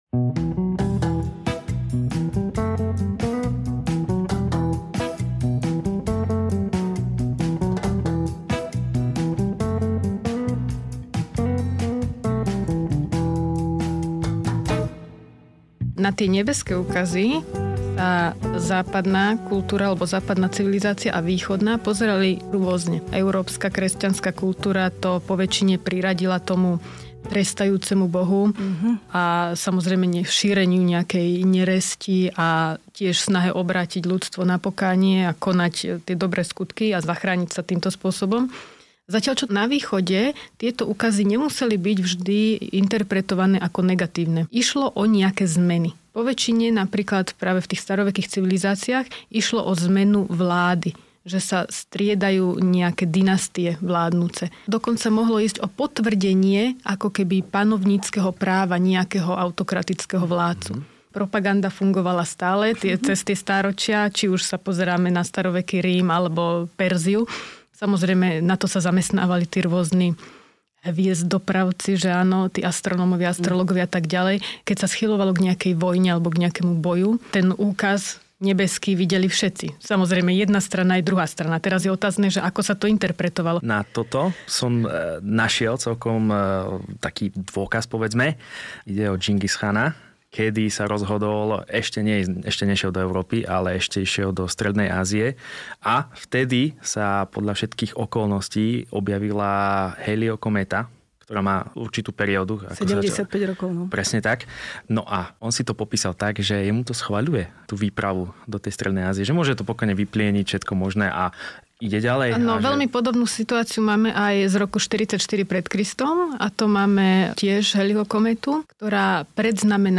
Kedy sa objavila hviezda s chvostom nad jasličkami? O betlehemskej hviezde, Hallyho kométe a ďalších nebeských úkazoch, ktoré šokovali ľudstvo, diskutujú meteorológovia